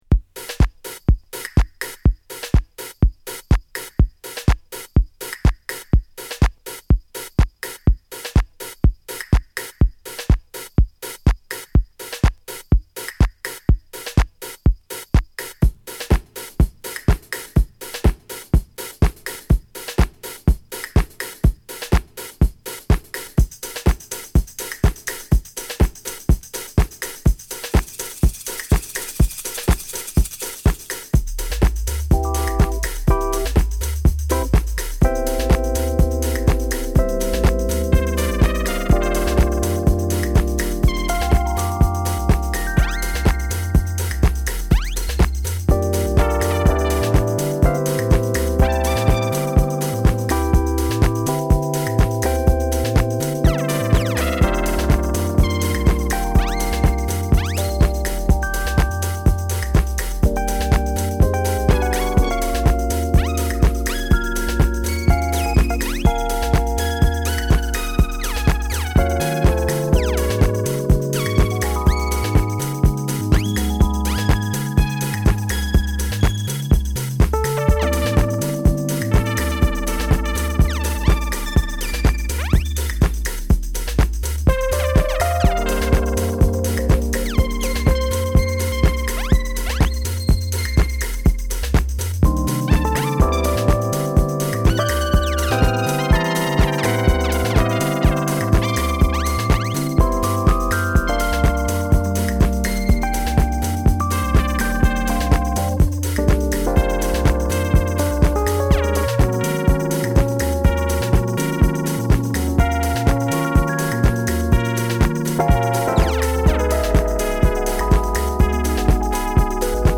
弾んだ生ドラムをfeat.したジャジーディープ・ハウス